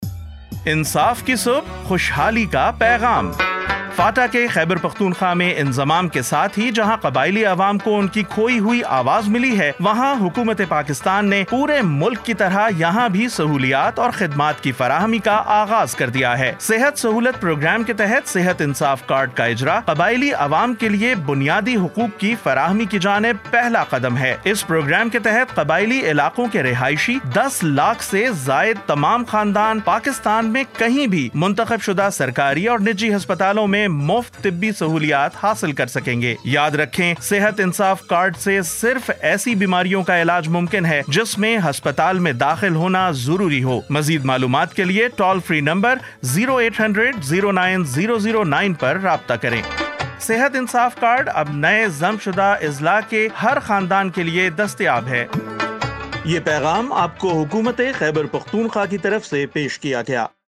Urdu Radio Spot-1
Urdu-Radio-Spot-1.mp3